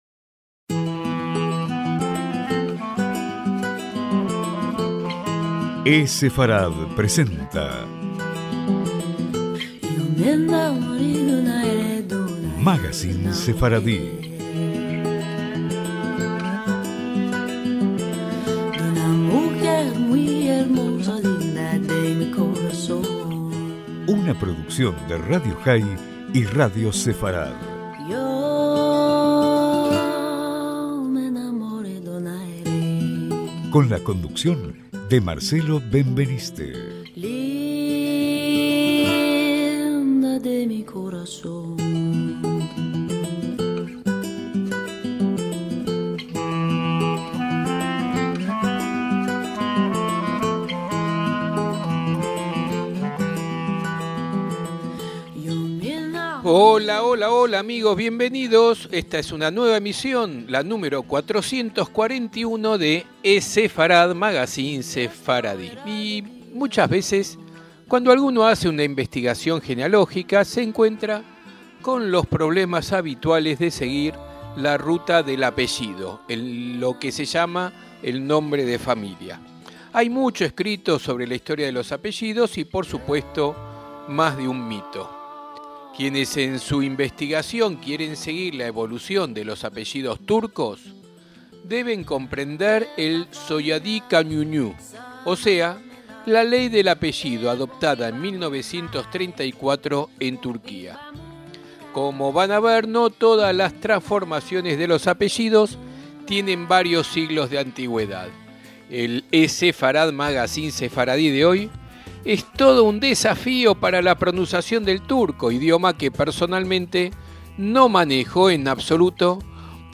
Si quieren en su investigación seguir la evolución de los apellidos turcos, deben comprender la “Soyadı Kanunu”, o sea, la Ley del apellido adoptada en 1934 en Turquía. El eSefarad Magacín Sefardí de hoy es todo un desafío para la pronunciación del turco, idioma que personalmente no manejo en absoluto, por lo que me anticipo a pedir las disculpas del caso por la mala pronunciación de estos apellidos que se sucederán a lo largo del programa.